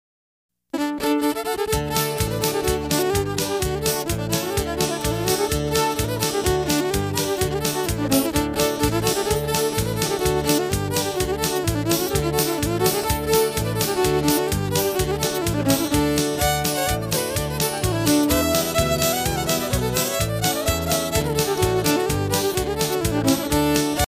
Fiddle Music